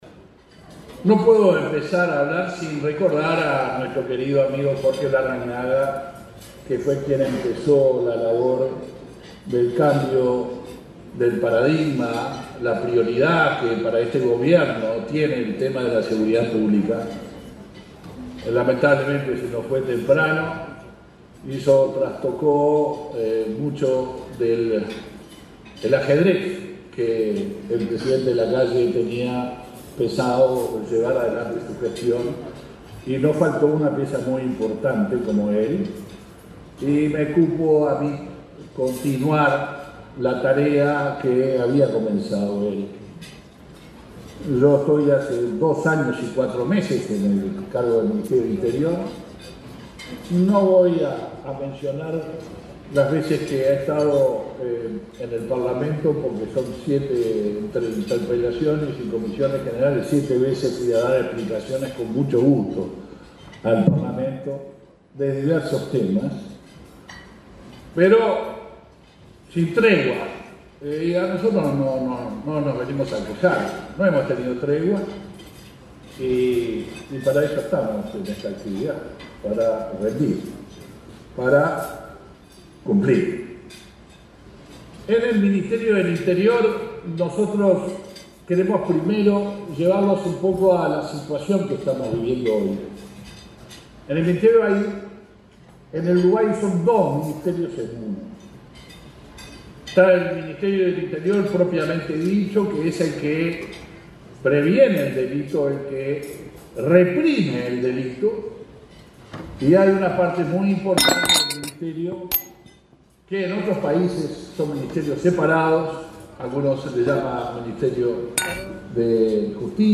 Disertación del ministro del Interior, Luis Alberto Heber
Disertación del ministro del Interior, Luis Alberto Heber 26/09/2023 Compartir Facebook X Copiar enlace WhatsApp LinkedIn Este martes 26 en Montevideo, el ministro del Interior, Luis Alberto Heber, disertó en un almuerzo de trabajo de la Asociación de Dirigentes de Marketing.